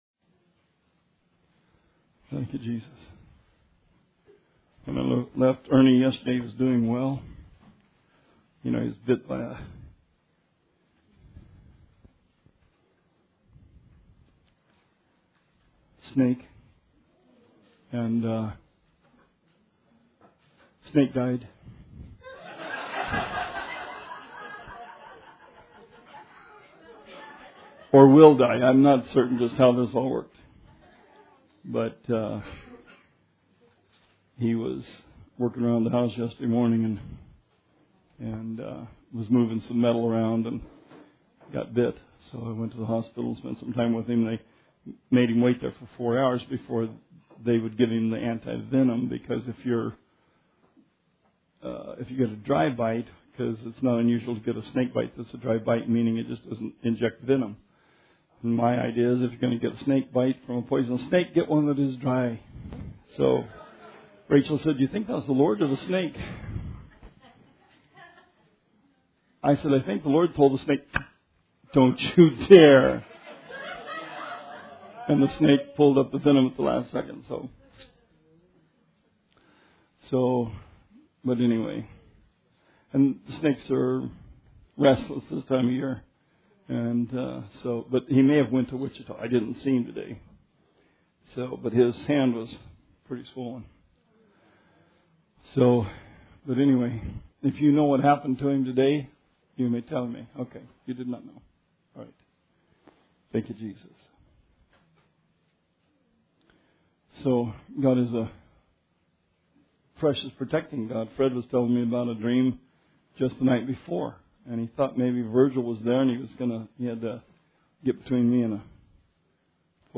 Bible Study 8/17/16